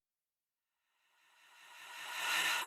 rev_breath